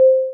First, listen to each of these frequencies in isolation (click the links below to hear each frequency - these tones were generated with computer software):
523.25 Hz